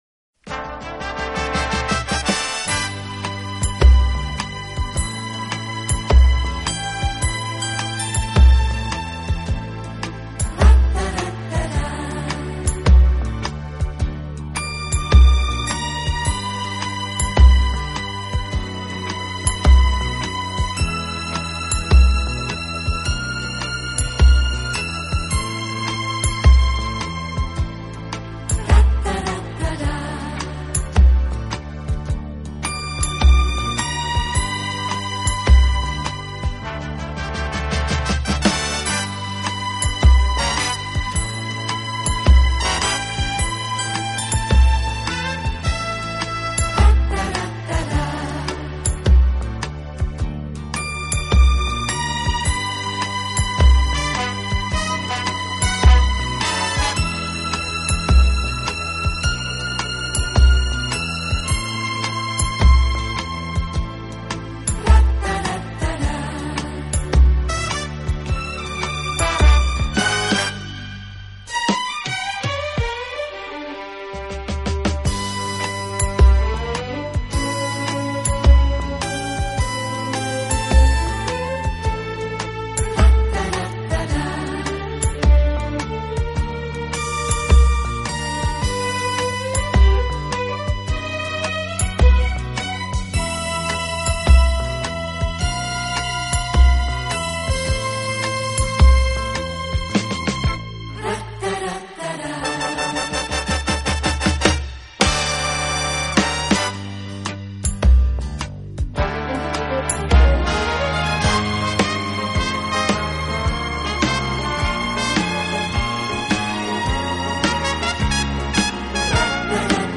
【顶级轻音乐】
管乐组合，给人以美不胜收之感。